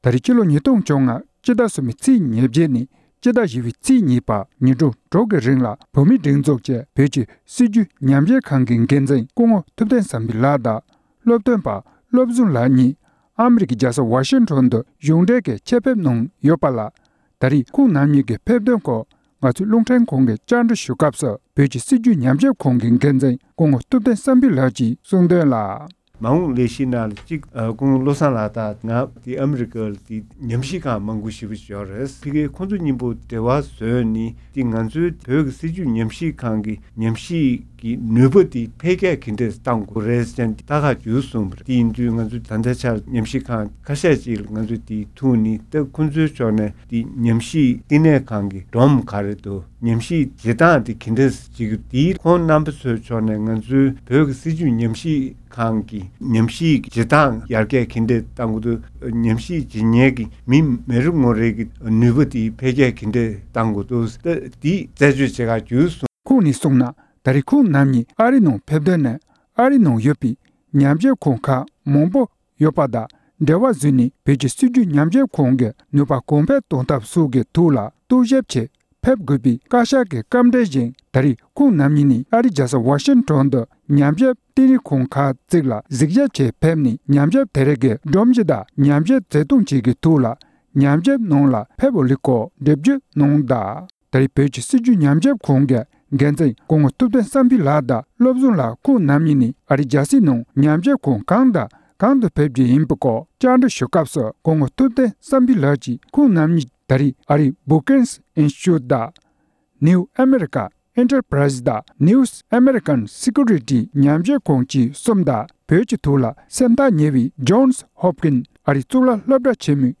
བཅར་འདྲི་ཞུས་པ།